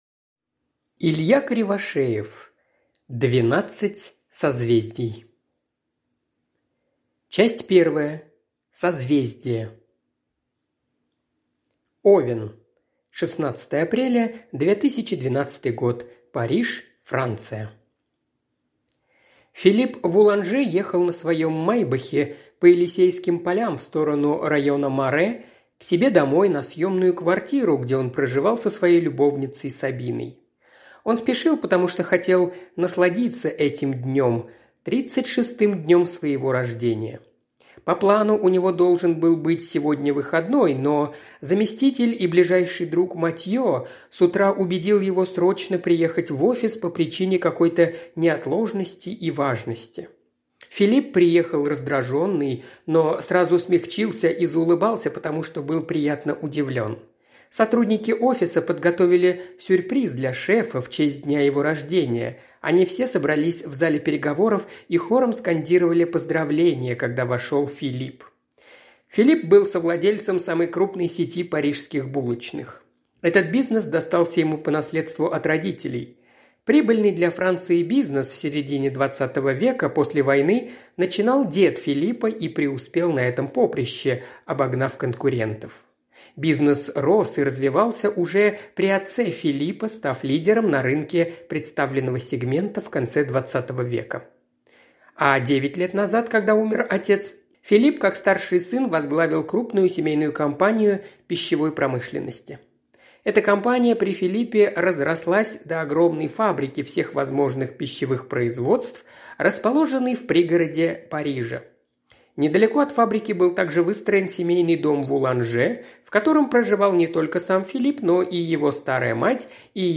Аудиокнига 12 созвездий | Библиотека аудиокниг